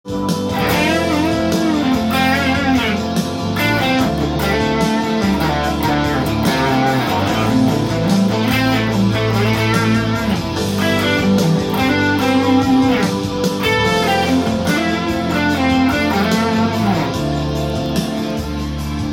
エレキギターで弾ける【歌うAマイナーペンタトニックスケール】シーケンスパターン【オリジナルtab譜】つくってみました
【歌うAmシーケンスパターンを使いアドリブでソロを弾いてみました】